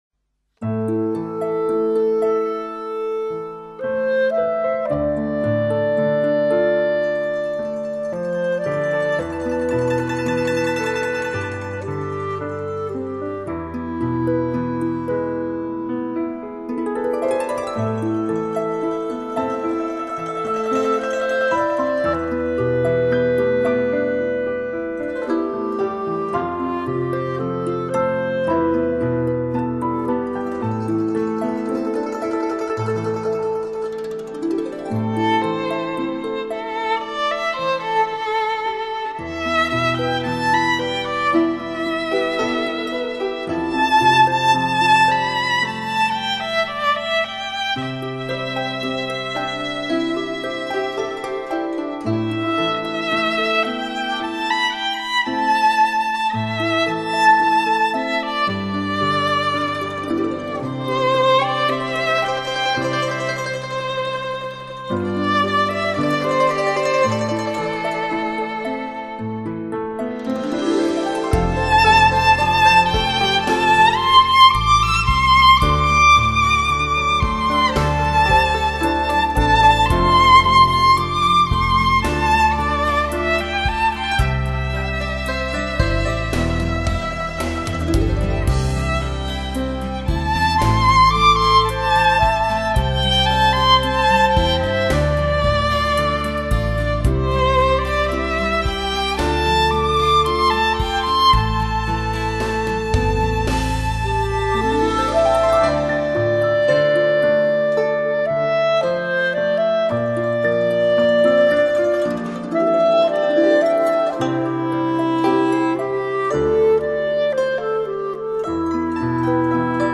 一幅唯美抒情的音乐画卷
小提琴演奏
小提琴演绎精彩藏歌！